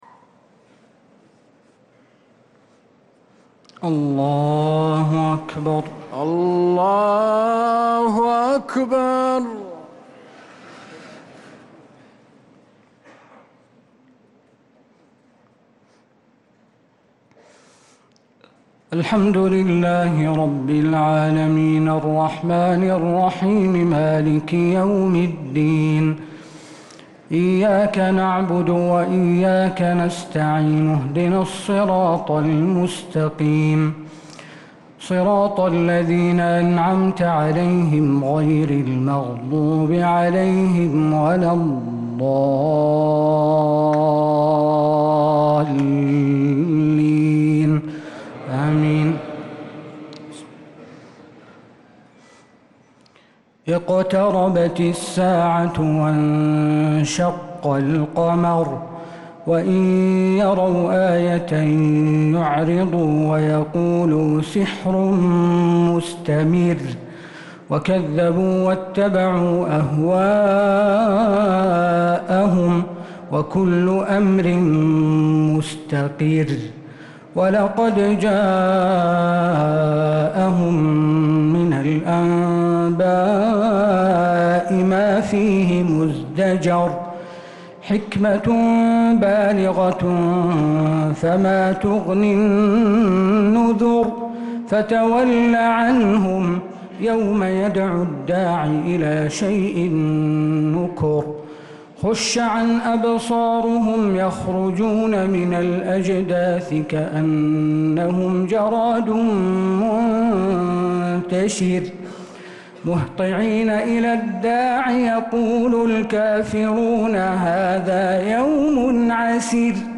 تراويح ليلة 27 رمضان 1446هـ من سورة القمر الى سورة الرحمن كاملة | Taraweeh 27th night Ramadan 1446H Surah Al-Qamar to Ar-Rahman > تراويح الحرم النبوي عام 1446 🕌 > التراويح - تلاوات الحرمين